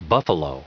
Prononciation du mot buffalo en anglais (fichier audio)
Prononciation du mot : buffalo